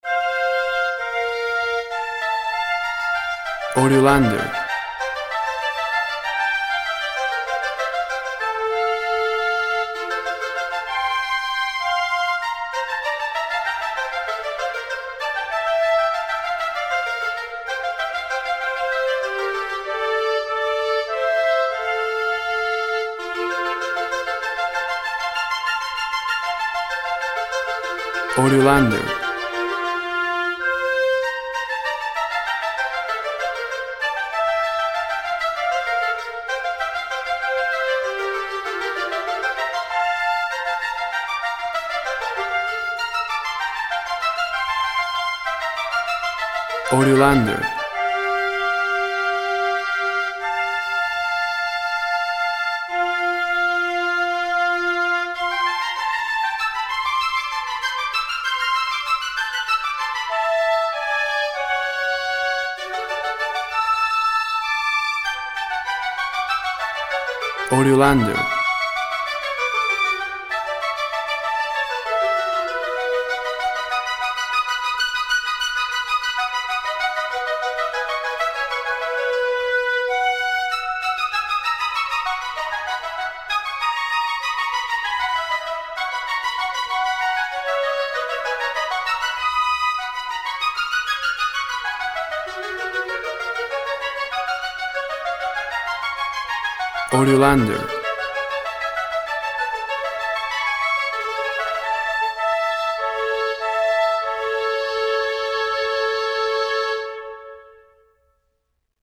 Duet, classical, flutes, variations, baroque music.
WAV Sample Rate 16-Bit Stereo, 44.1 kHz
Tempo (BPM) 97